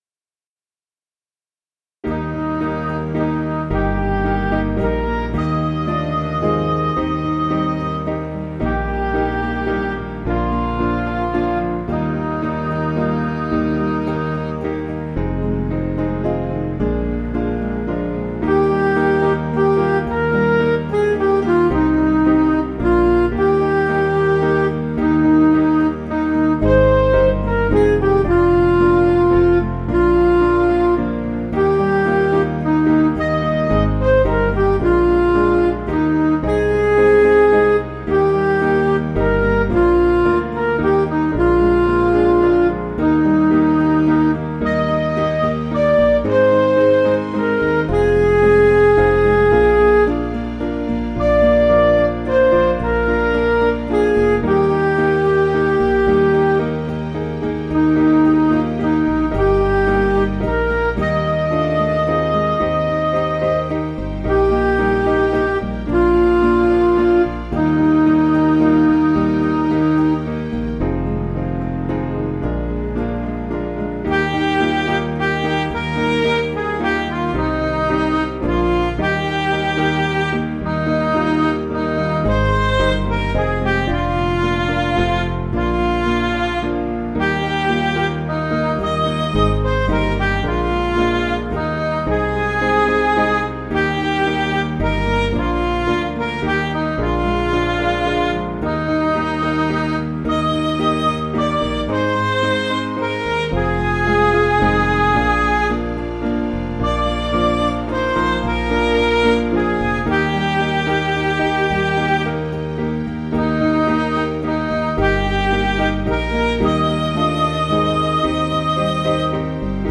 Piano & Instrumental
(CM)   3/Eb
Midi